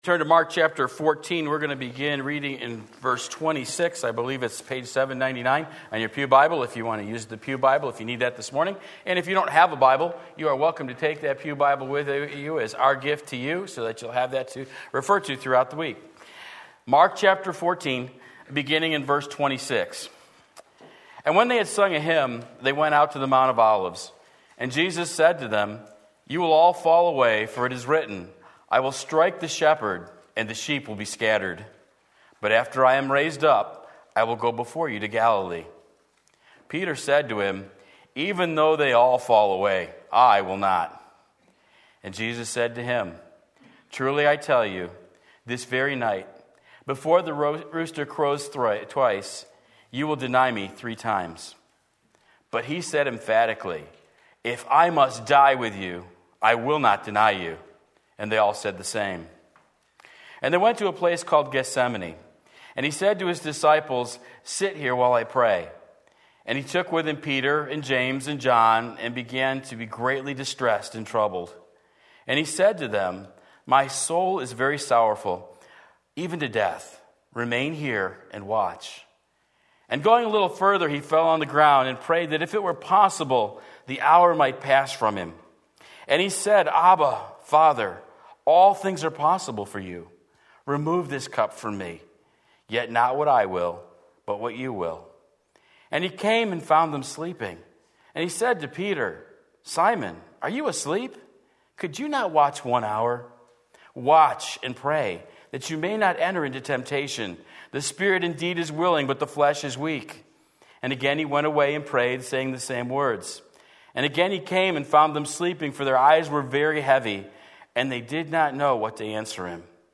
Sermon Link